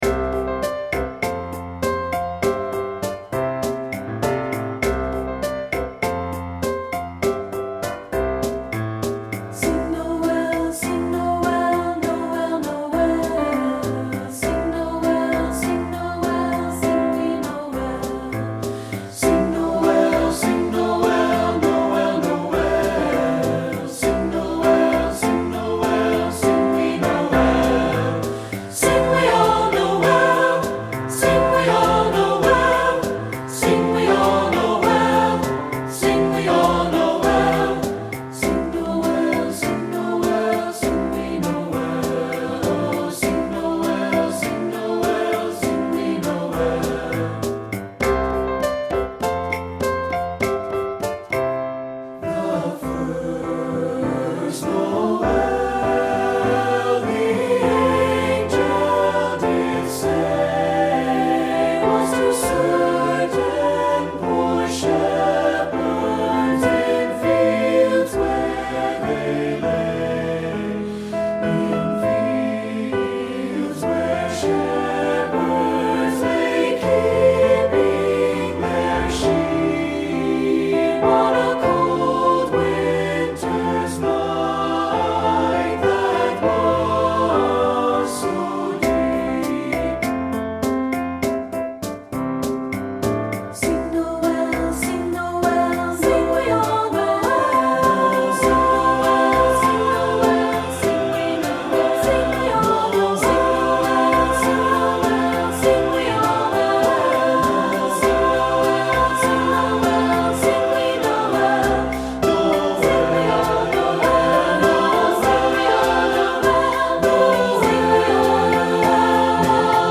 Composer: Spirituals
Voicing: SATB